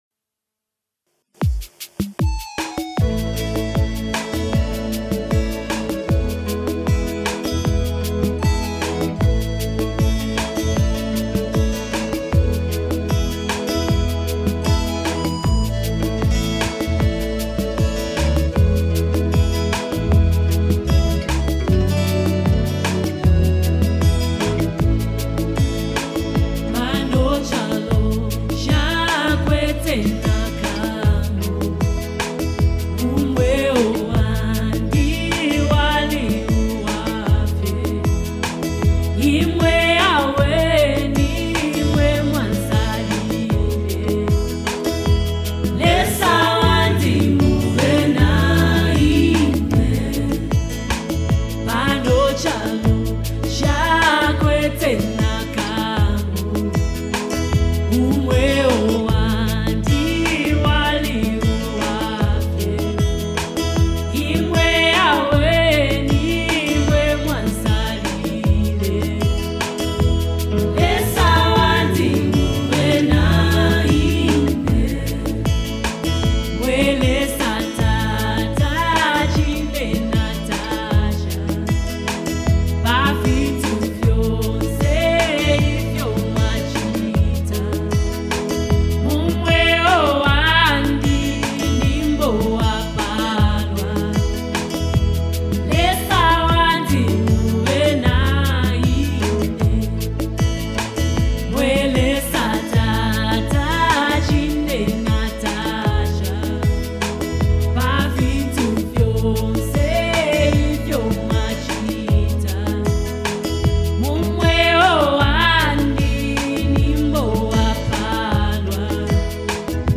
A Soul-Stirring Gospel Anthem
Zambian gospel artist
heartfelt vocals
emotional delivery